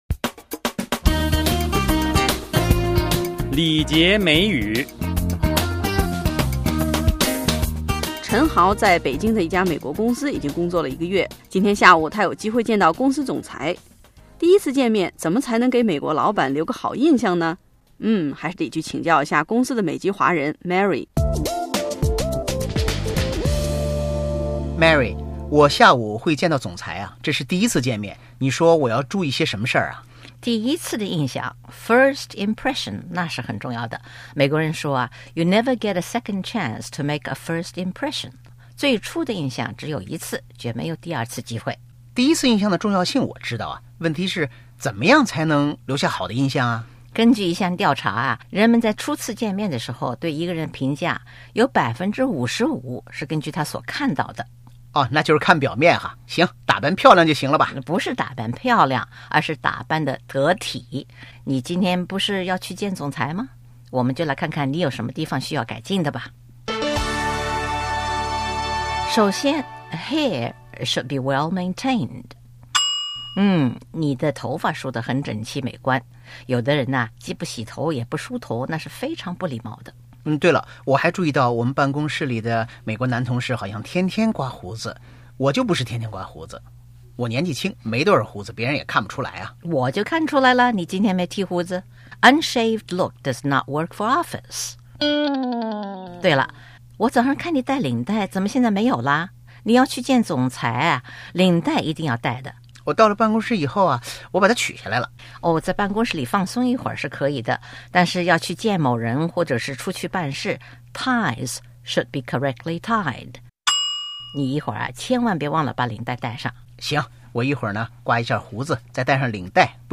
(Office ambience)